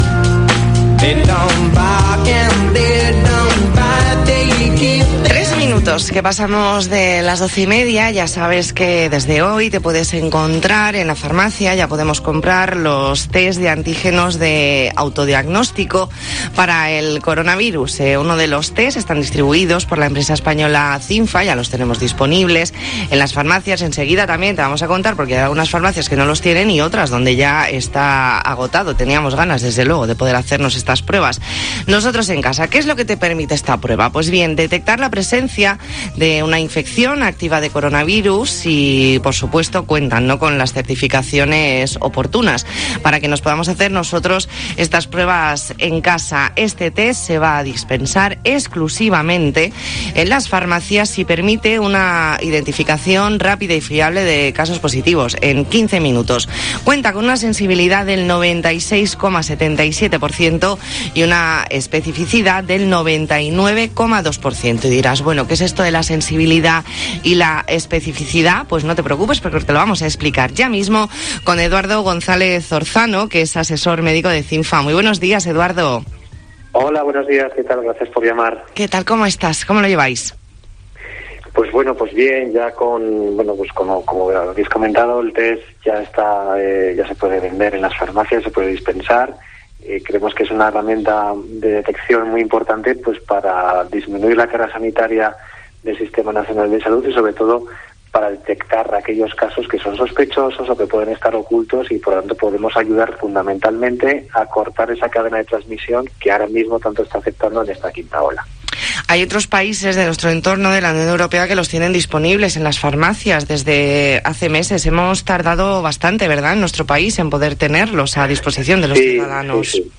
Entrevista en La Mañana en COPE Más Mallorca, jueves 22 de julio de 2021.